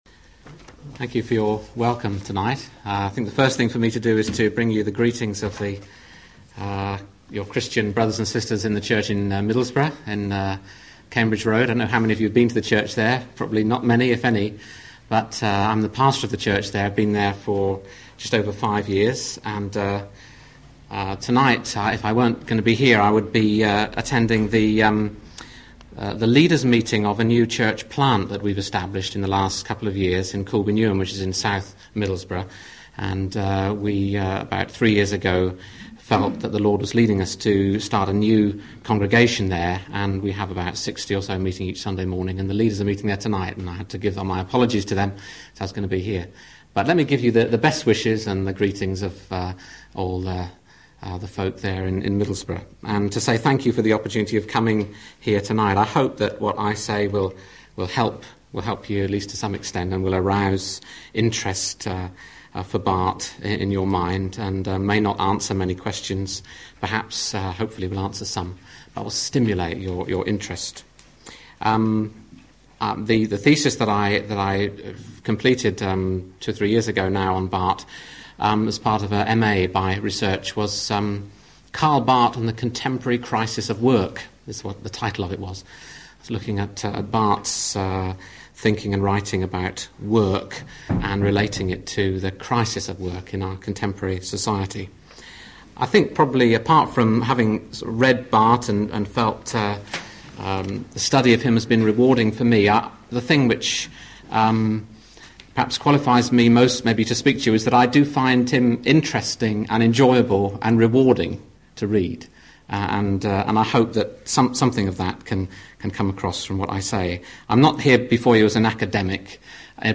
aut_lecture5_1991.mp3